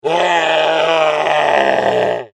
Ребята,кто может достать и предоставить звук,когда призрак конфетку забирает?)